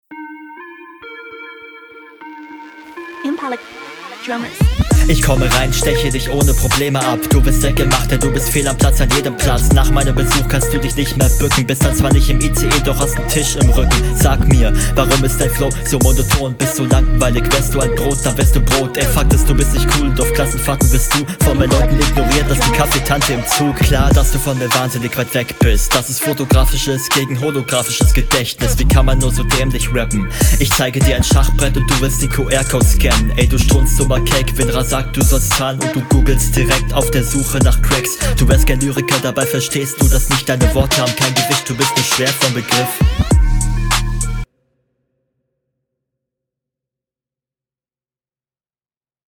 Flow: Alles sehr sauber gerappt und cool geflowt, aber Stimmeinsatz find ich etwas zu low, …
Soundqualität: Meh, weißt du wahrscheinlich selbst. Die S Laute sind nicht grade nice, aber du …
Flowlich ist das hier sehr solide, finde das stimmlich allerdings etwas monoton und lustlos gerappt, …